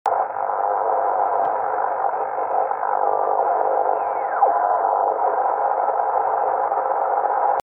Будто кисточкой шириной 5-10 кГц, водят по спектральному полотну :unsure:
Меняю частоту с 14055 до 14080, в середине записи писк FT8.